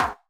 soft-hitwhistle.ogg